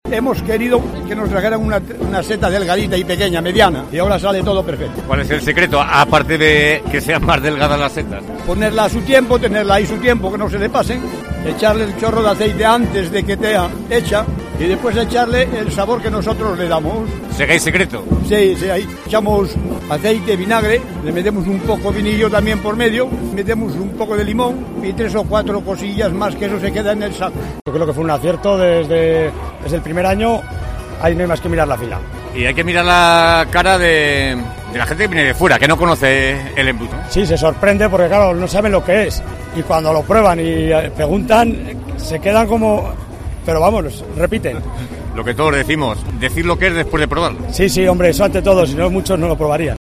Tercera jornada de la Semana Gastronómica, organizada por la Federación de Peñas, en la plaza del Mercado.